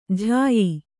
♪ jhāyi